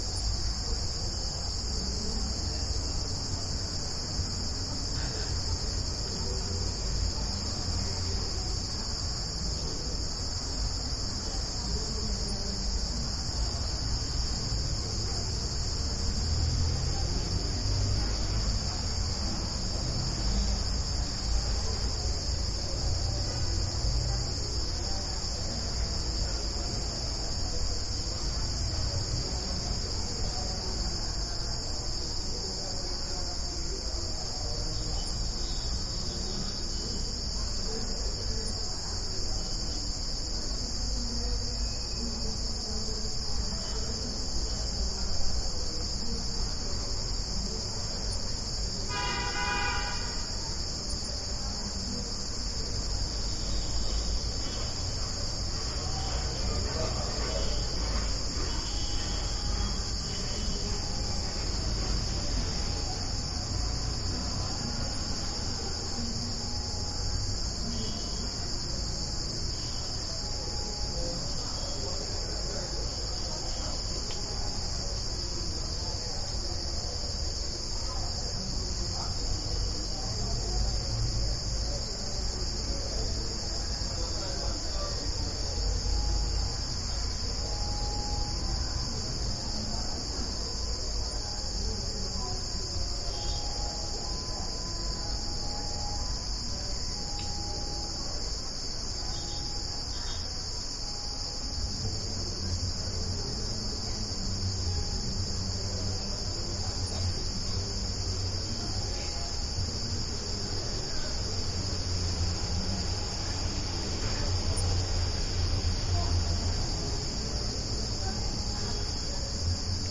乌干达 " 蟋蟀的夜晚响亮的小墙后院酒店+附近的声音回声和重低音交通 坎帕拉，乌干达，非洲 201
描述：蟋蟀夜晚大声小围墙后院酒店+附近的声音回声和重低音交通坎帕拉，乌干达，非洲2016.wav
Tag: 响亮 后院 夜间 乌干达 交通 非洲 酒店 蟋蟀 城市